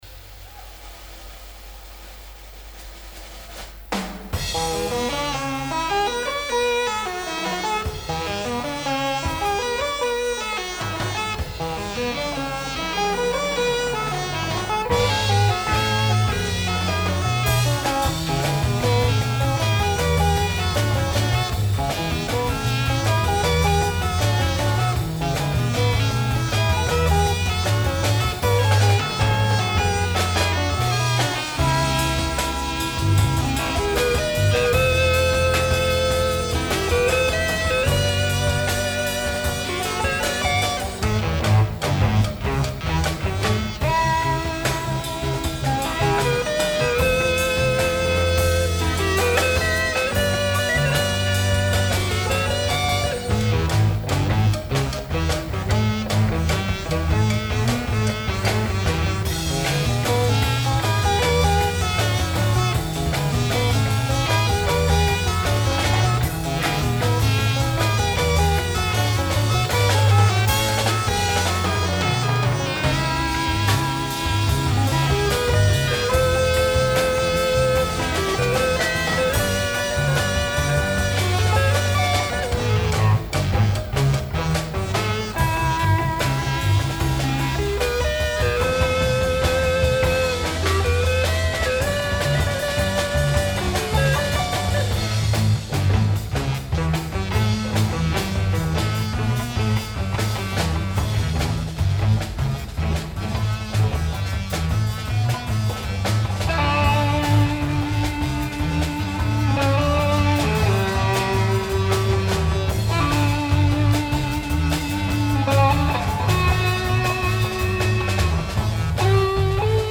Below is a sample, recorded badly, live but alive…
Their influences were mainly from jazz and “jazz-rock Fusion”, an altogether more technical and challenging playing style.
For example, we routinely played and jammed in time signatures such as eleven-eight.
We, later named “Inversion”, were not polished by any means, and we were far from professional in our presentation and sound quality.